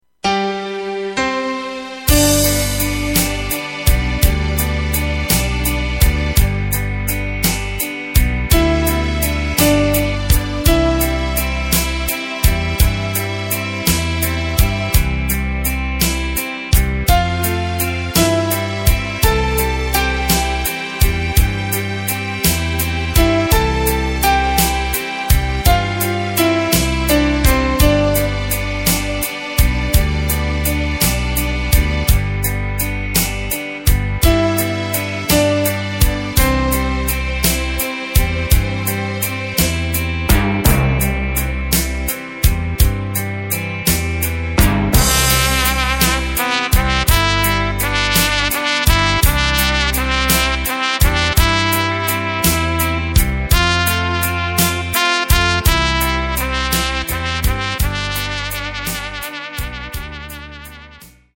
Takt:          4/4
Tempo:         65.00
Tonart:            C
Instrumental für Trompete!